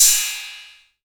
808CY_2_Tape.wav